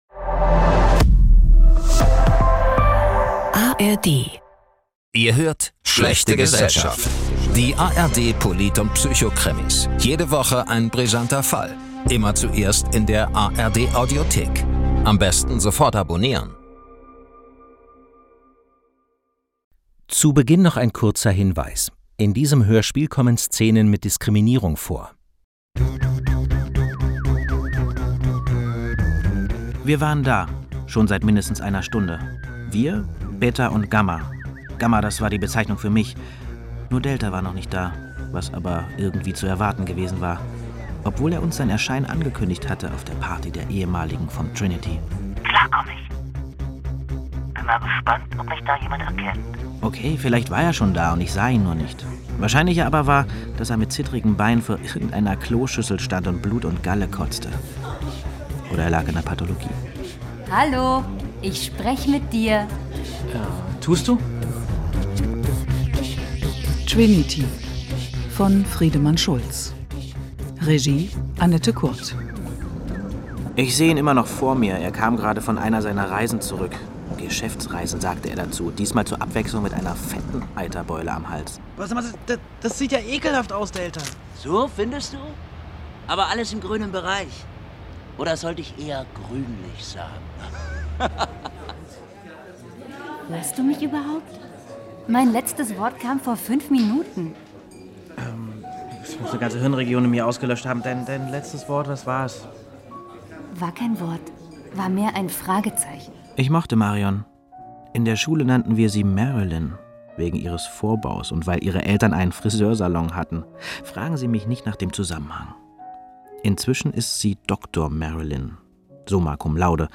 Trinity – Krimi über die dunkle Seite der Forschung